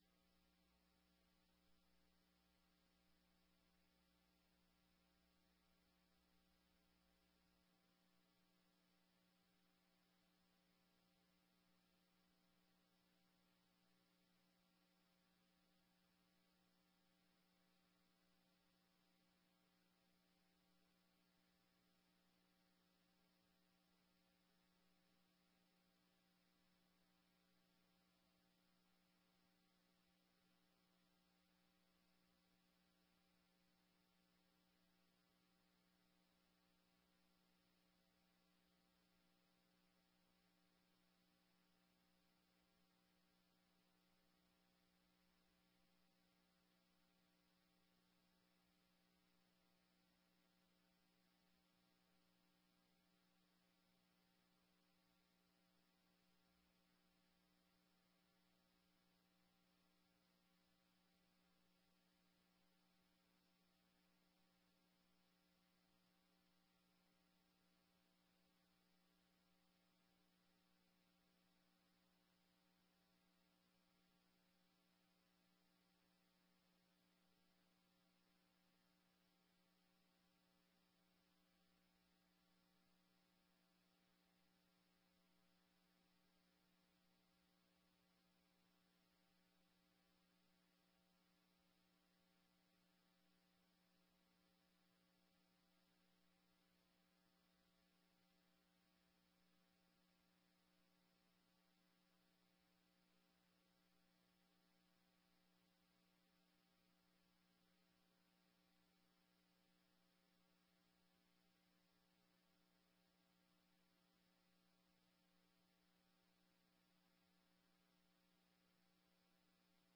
21ª Sessão Ordinária de 2019